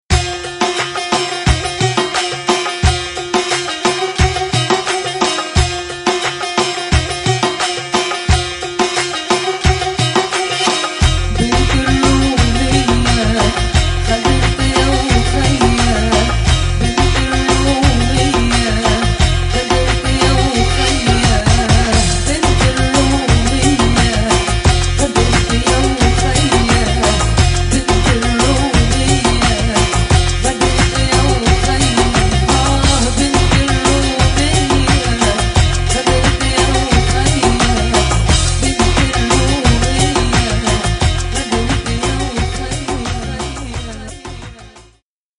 アラブ〜マグレブ〜インドの伝統的音楽に、ダブやアフロビートをミックス。